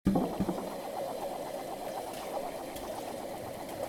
A segment of the "Rice Water" audio file, with a spatial filter, and and audio effect layered on top. This sound is correlated with the letter "y" on the computer keyboard.